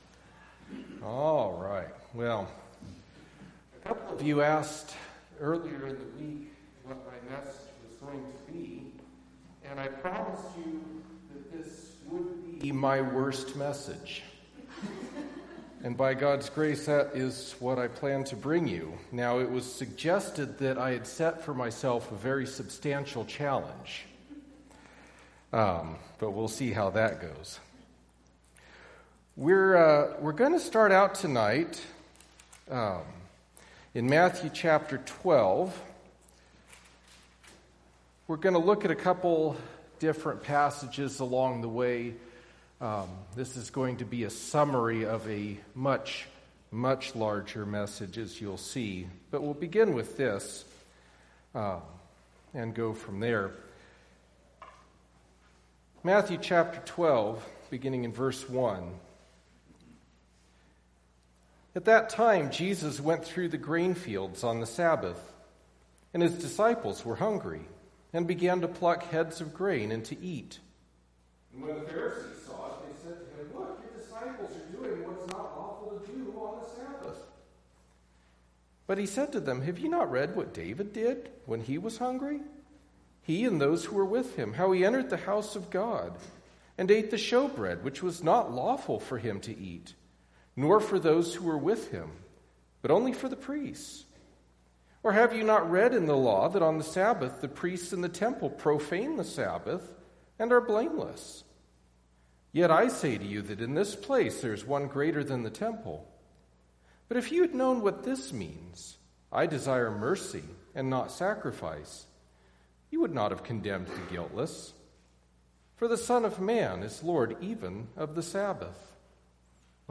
Passage: Matthew 12:1-8 Service Type: Wednesday Evening Topics